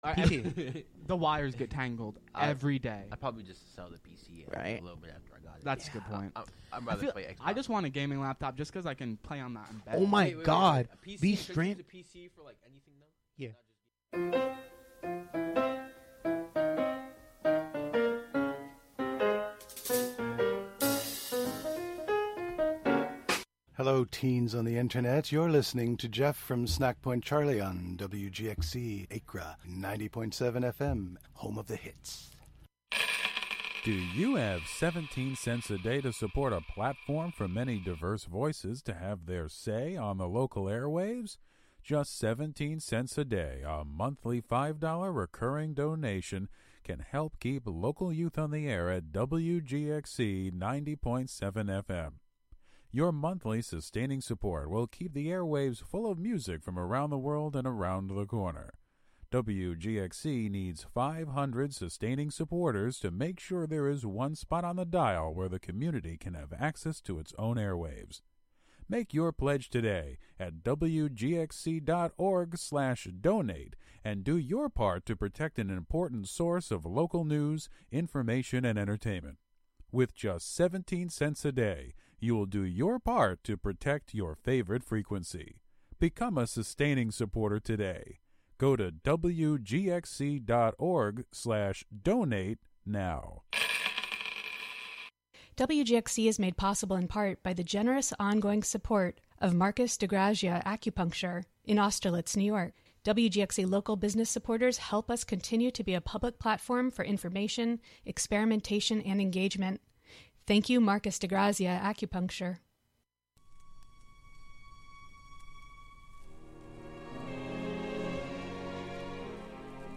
Featuring gospel, inspirational, soul, R&B, country, christian jazz, hip hop, rap, and praise and worship music of our time and yesteryear; interwoven with talk, interviews and spiritual social commentary